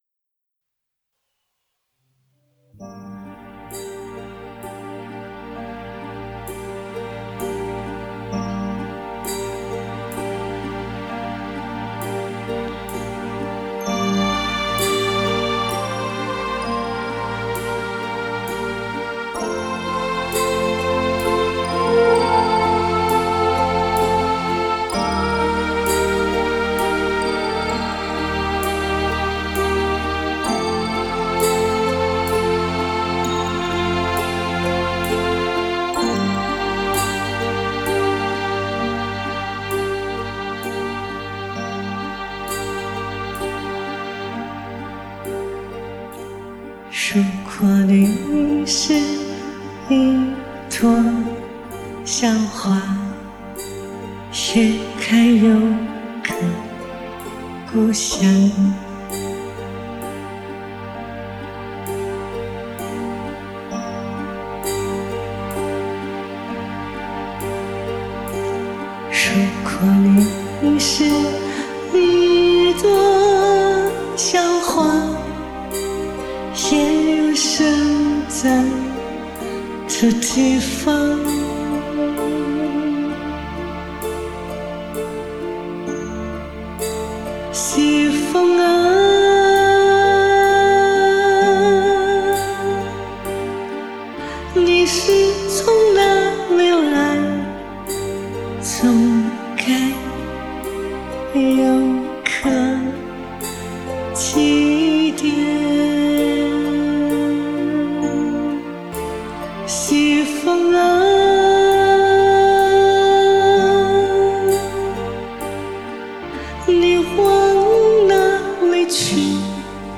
弹唱